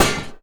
metal_solid_hard1.wav